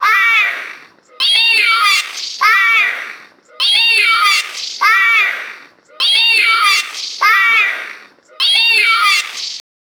children-shouting-hey-100-eul3inye.wav